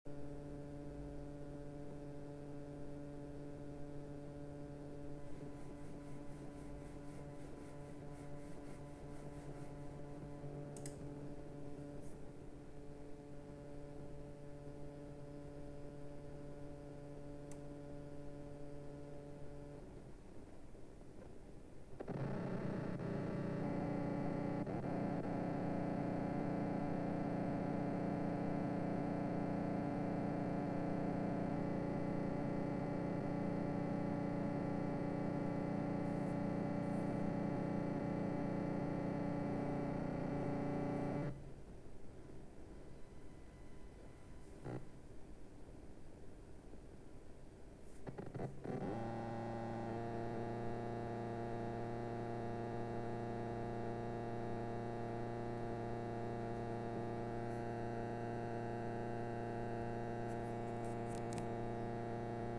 Störgeräusche
Ein "Flimmern" und bei starker Auslastung des PCs und Mausbewegungen wurde es schlimmer.
Hab dafür eine Box auf volle Lautstärke gestellt, damit man die Geräusche besser hört. bis 0:05 reines Rauschen im Idle 0:05 bis 0:10 Mausbewegung 0:20 bis 1:00 Spiel wird gestartet Anhänge Aufnahme_1.wav 2,6 MB